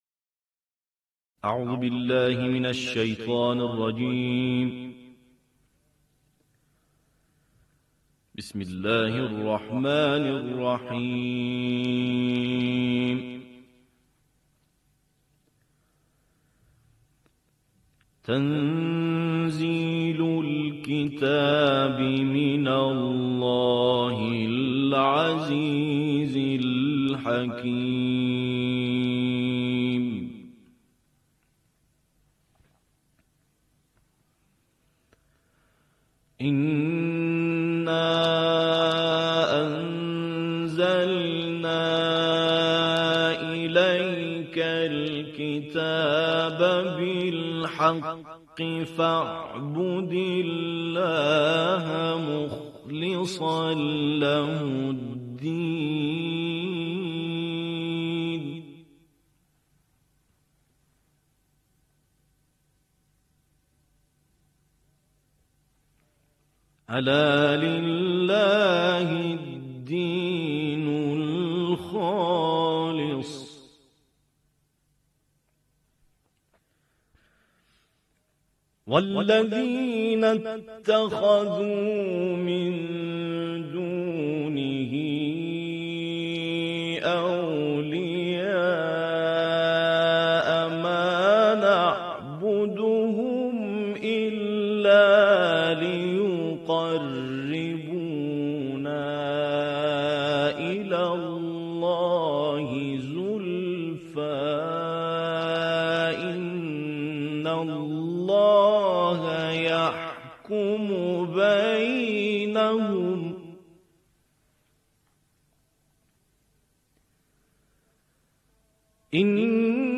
تلاوت تحقیق